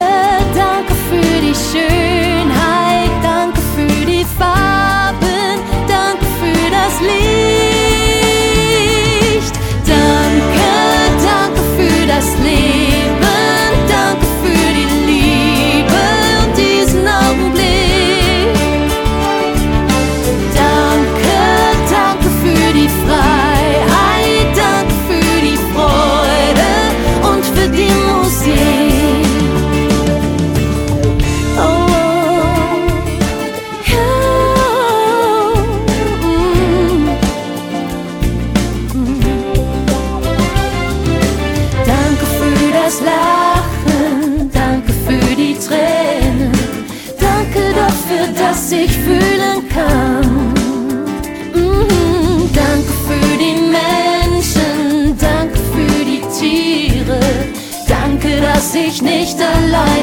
Pop & Rock, Worship 0,99 €